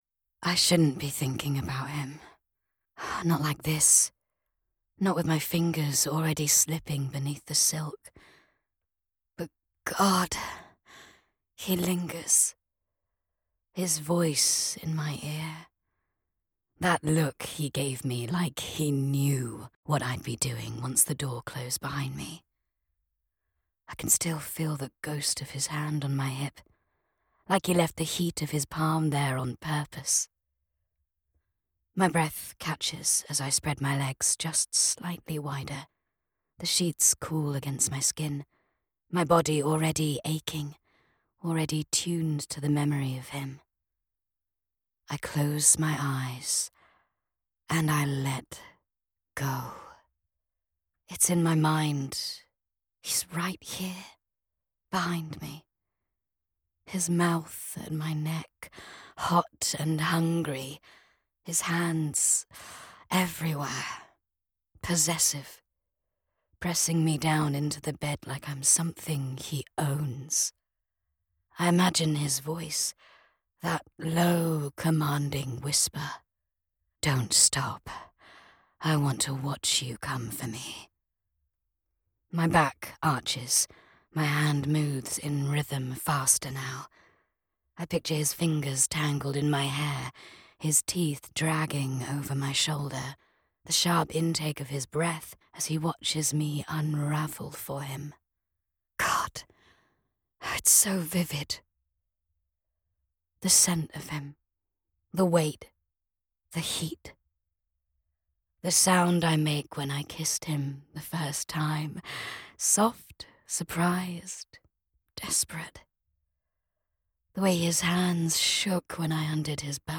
1ST PERSON ROMANCE, F, BRITISH NEUTRAL
Custom built broadcast quality studio